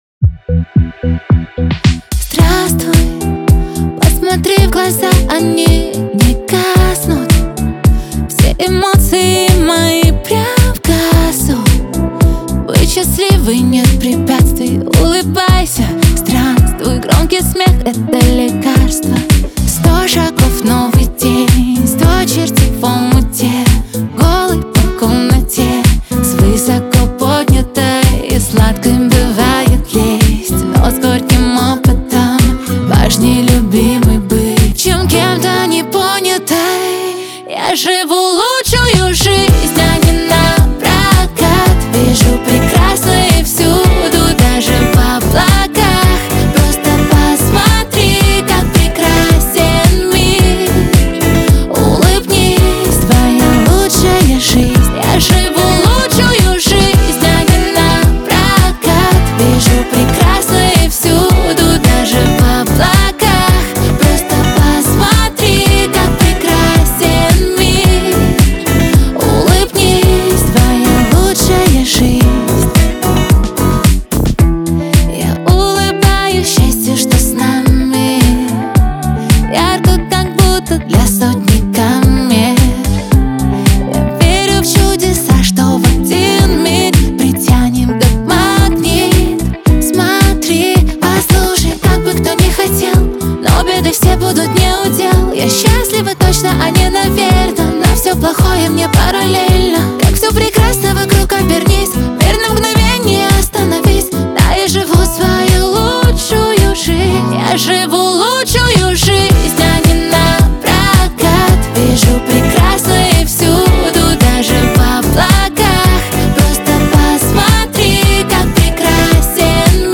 pop
эстрада
диско